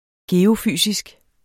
Udtale [ ˈgeːo- ]